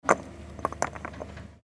Gemafreie Sounds: Baustelle
mf_SE-3996-bricks_chattering.mp3